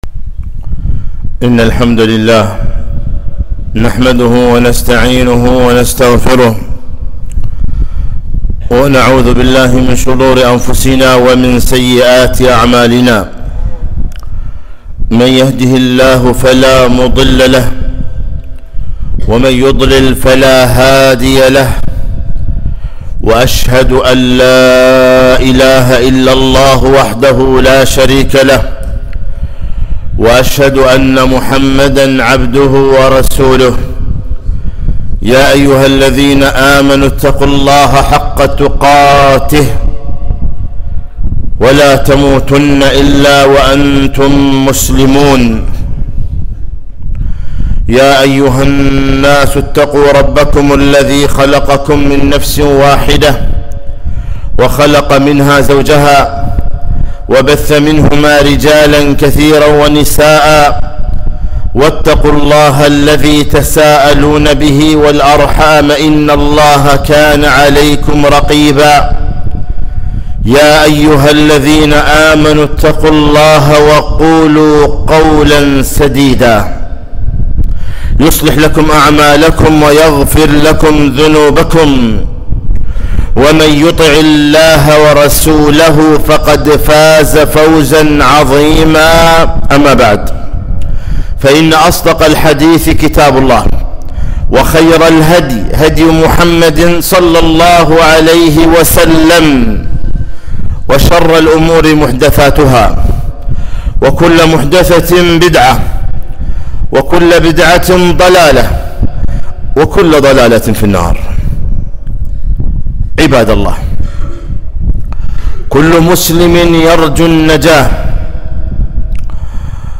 خطبة - هل تريد النجاة؟